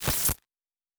pgs/Assets/Audio/Sci-Fi Sounds/Electric/Glitch 2_02.wav at master
Glitch 2_02.wav